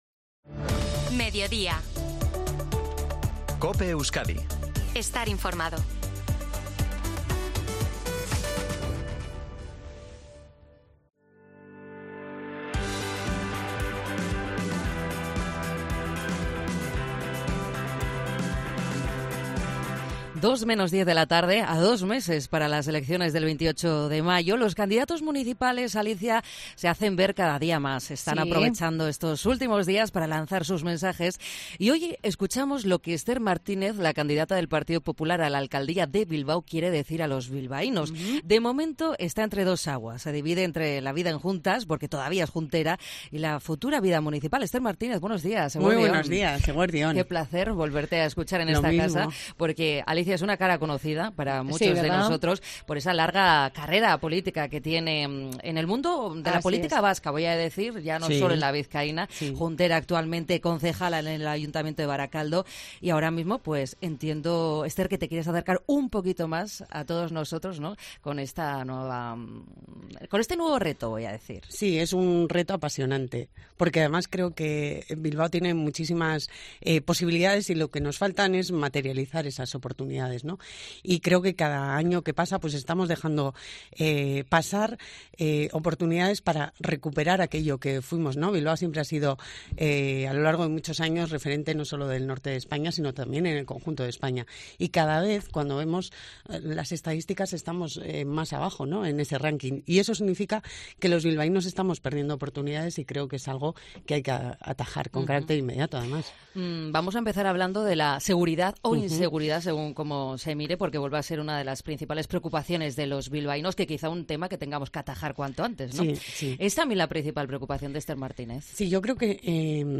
Entrevista a Esther Martínez en COPE Euskadi